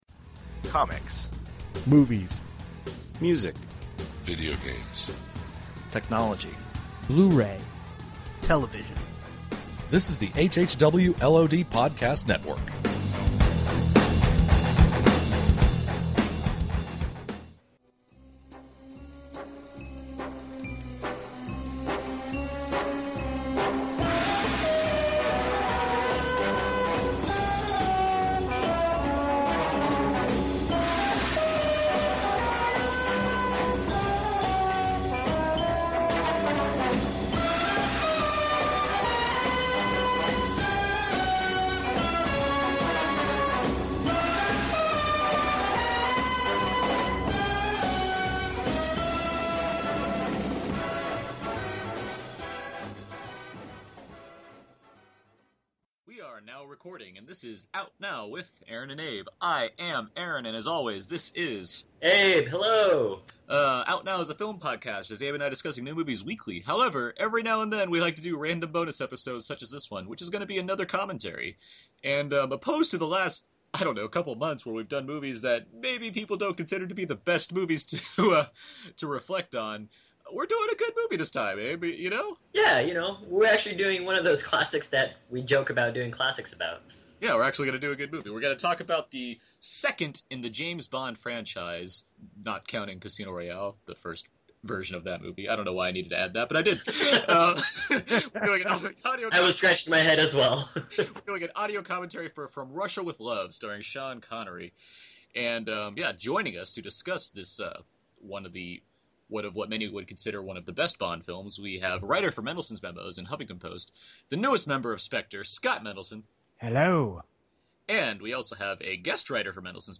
Out Now Bonus - From Russia With Love Audio Commentary
The group goes through a lengthy discussion while watching the film, which can be synched up for those who would want to watch along, but for everyone in general, this commentary track is just a strong source of fun banter revo